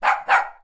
动物园 西施犬 " 狗狗西施犬吠声 x2 02
描述：西施犬，吠叫
标签： 吠叫 动物 施姿 树皮
声道立体声